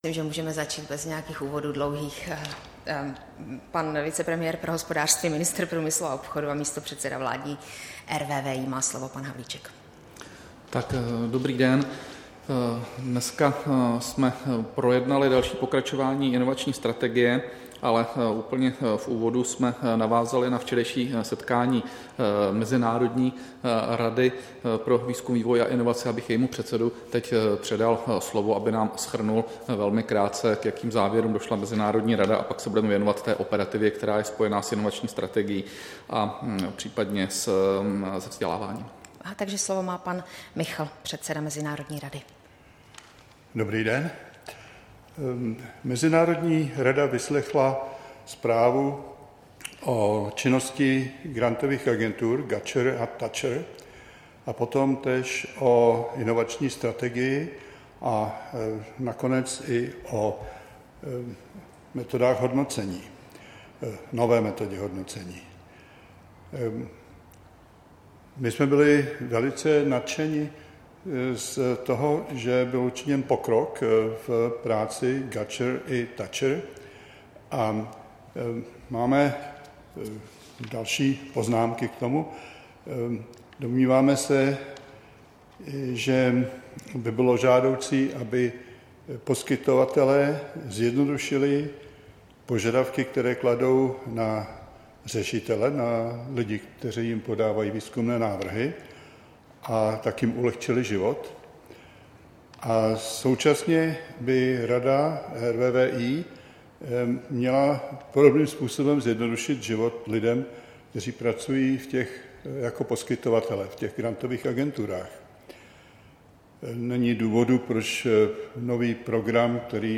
Tisková konference po jednání Rady pro výzkum, vývoj a inovace, 31. května 2019